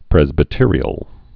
(prĕzbĭ-tîrē-əl, prĕs-)